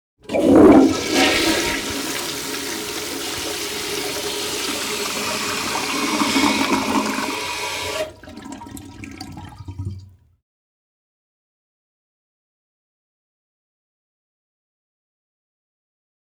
Traditionelle Spülung Standardspülung Moderne Spülung